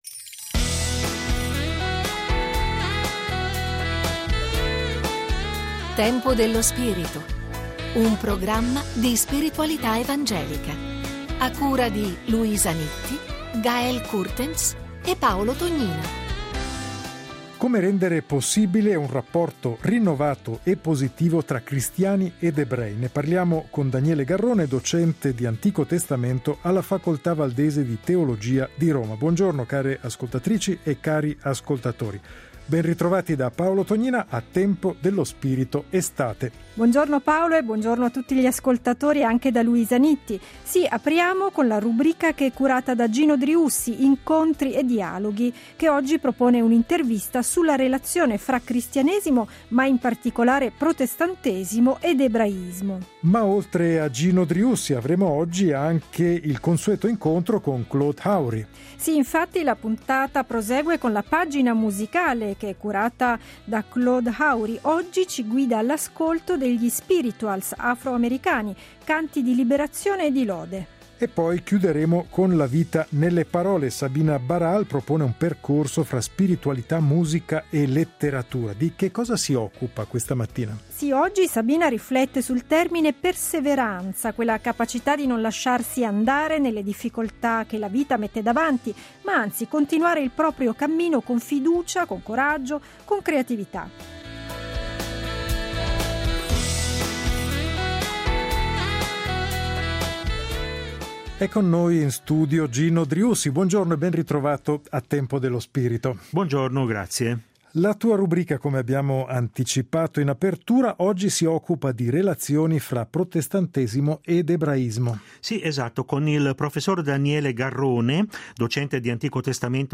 Intervista
Questa settimana: gli Spirituals afroamericani, canti di liberazione e di lode .
Scopri la serie Tempo dello spirito Settimanale di spiritualità evangelica.